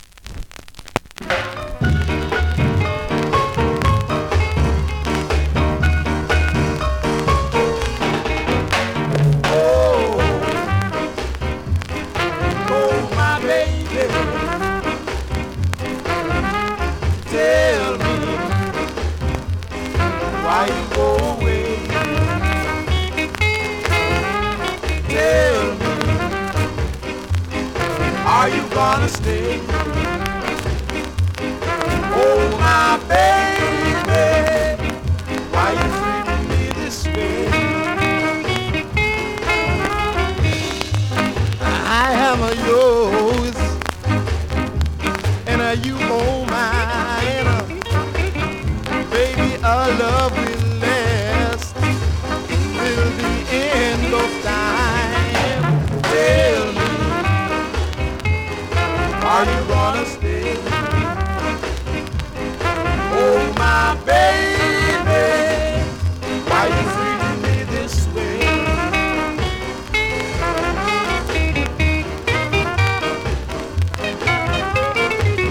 はじめ少しノイズ、プレイはOK)   コメントレアSKA!!
スリキズ、ノイズそこそこありますが